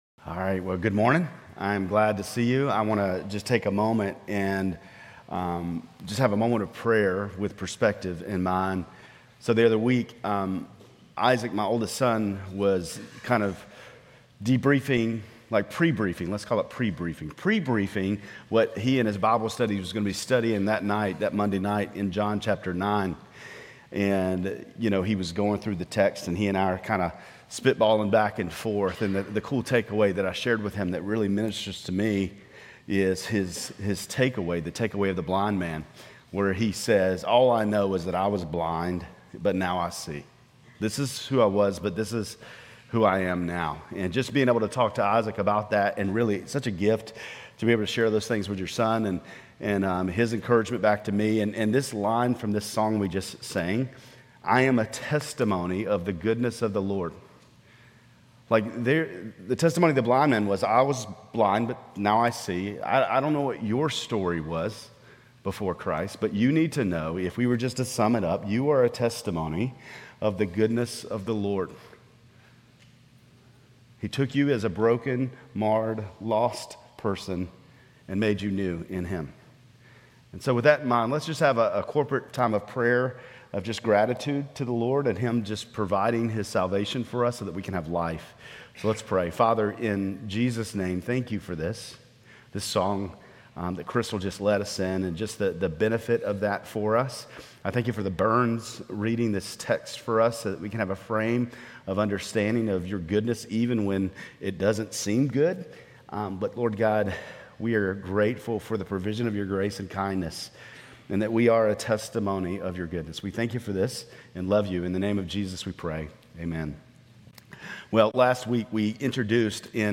Grace Community Church Lindale Campus Sermons Gen 32:22-32 - Jacob wrestling with God Nov 17 2024 | 00:26:46 1x 00:00 / 00:26:46 Subscribe Share RSS Feed Share Link Embed